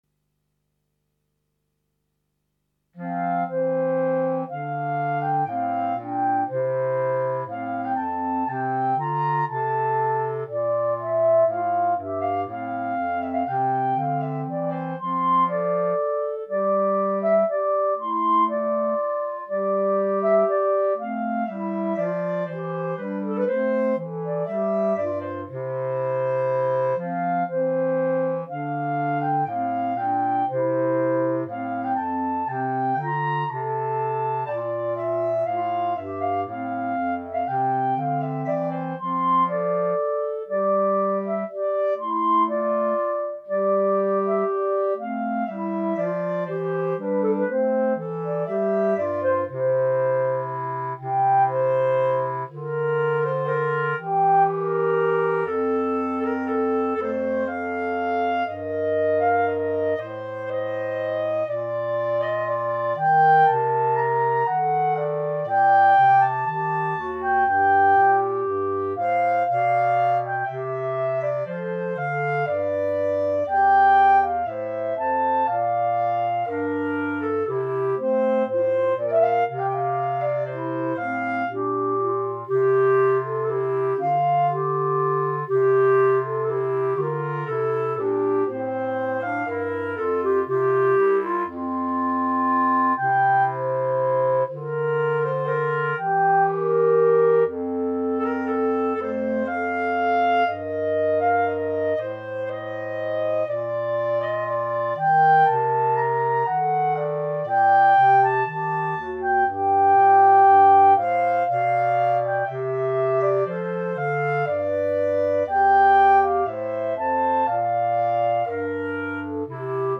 minus Clarinet 1